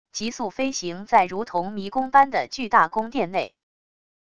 急速飞行在如同迷宫般的巨大宫殿内wav音频